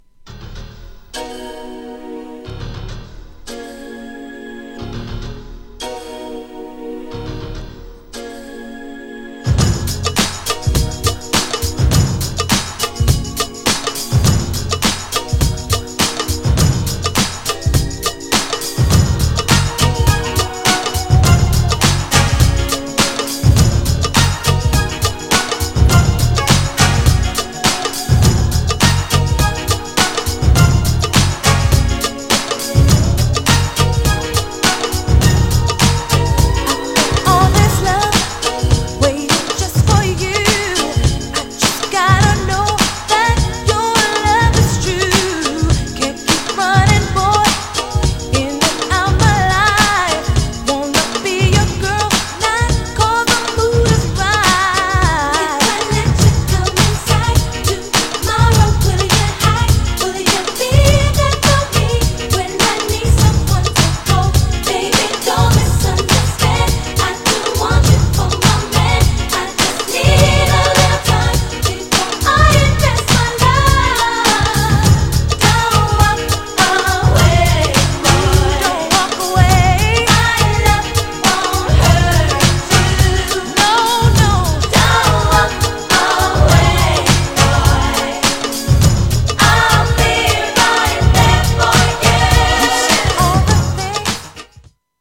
ハネ系ビートにPOPなコーラスが爽やかな女性ボーカルグループ!! ソウルもあるけどキャッチーなテイストでいい感じ!!
GENRE R&B
BPM 101〜105BPM
# HIPHOPテイスト
# NEW_JACK # ちょいハネ系 # キャッチーなR&B
# ホッコリした歌モノ # 女性コーラスR&B